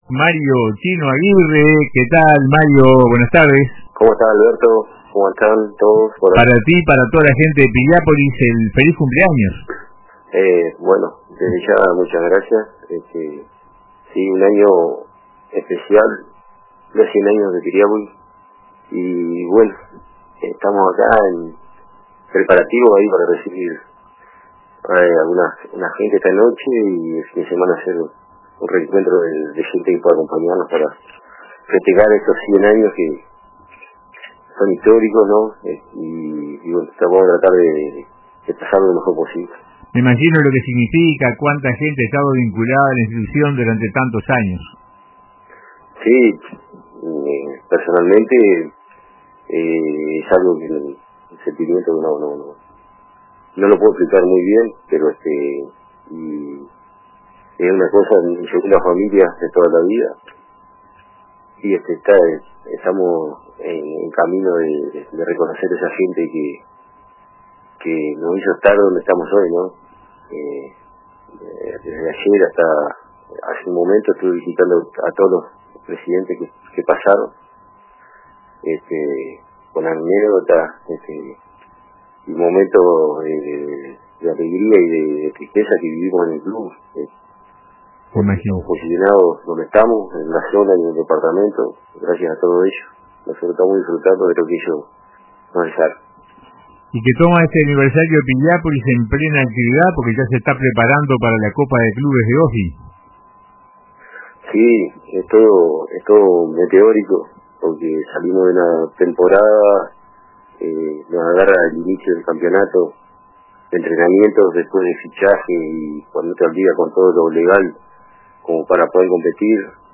“Es un año especial”, dijo, “estamos preparándonos para recibir a algunas personas esta noche y para un reencuentro este fin de semana. Invitamos a todos a unirse a nosotros para celebrar estos 100 años históricos”.